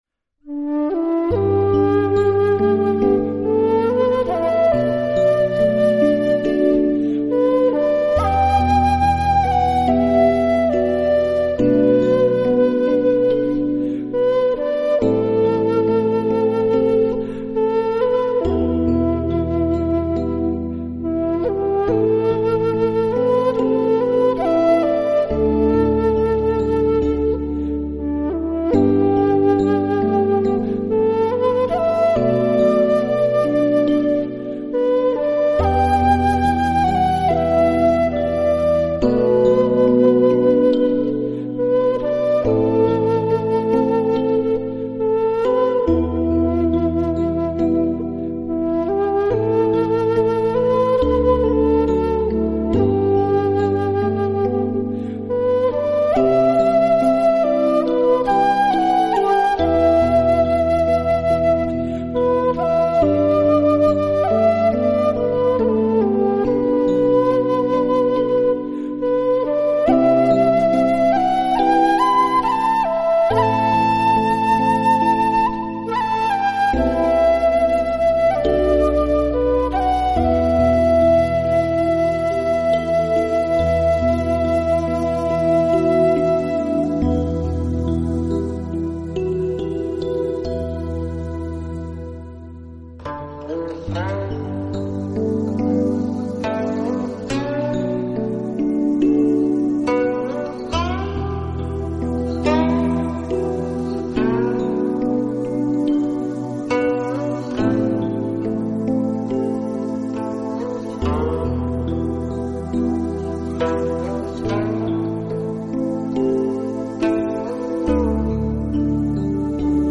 佛音 冥想 佛教音乐